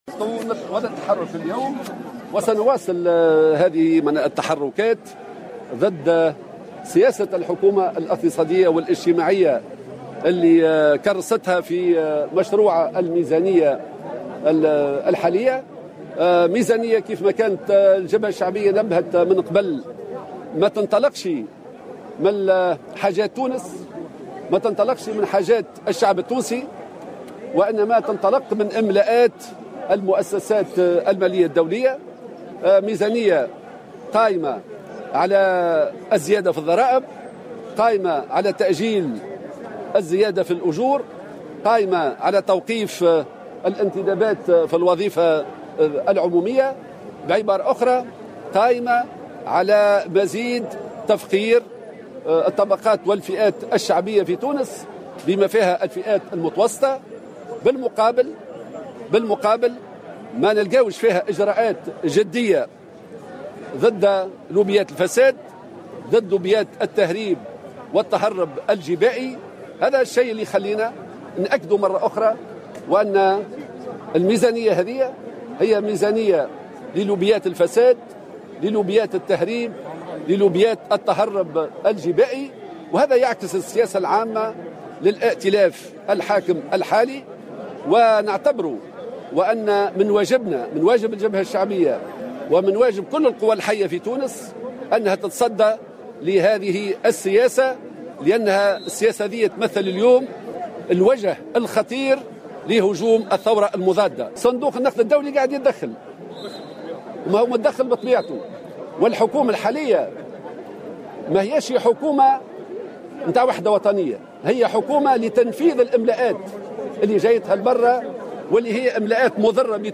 قال الناطق الرسمي للجبهة الشعبية، حمة الهمامي، في تصريح لمراسل الجوهرة أف أم، على هامش وقفة احتجاجية نظمتها الجبهة اليوم السبت ضد مشروع قانون المالية لسنة 2017، إن الحكومة كرست من خلال هذا المشروع سياساتها القائمة على إملاءات المؤسسات المالية الدولية، وفق تعبيره.